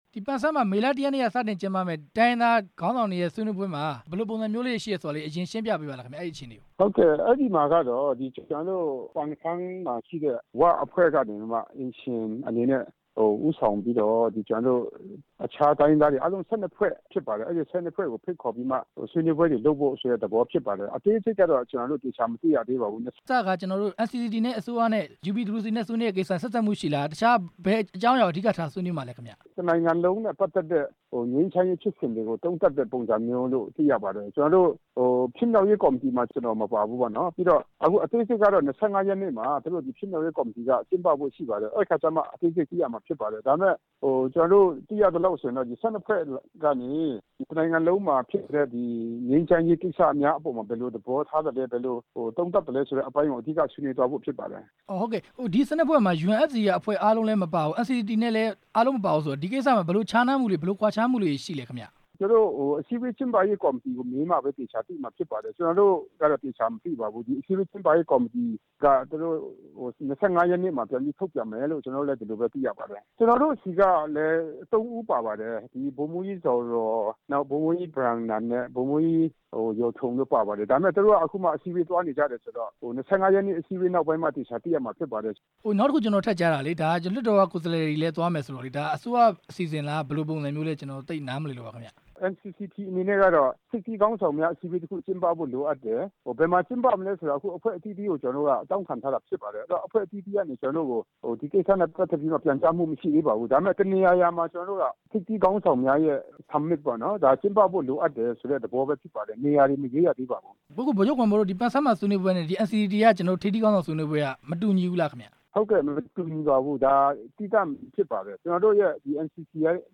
ဗိုလ်ချုပ်ဂွမ်မော်ကို မေးမြန်းချက်